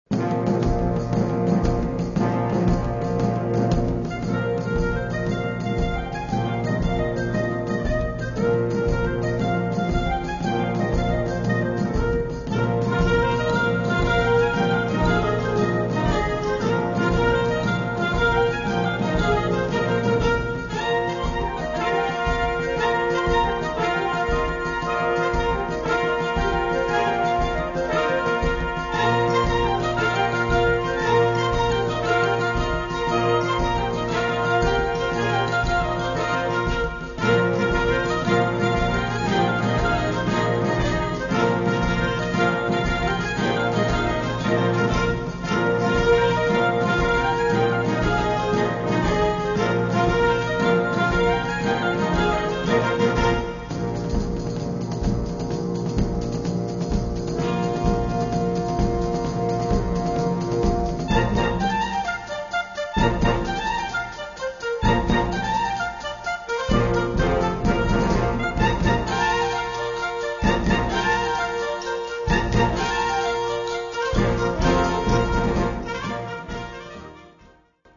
Komponist: Traditionell
Besetzung: Blasorchester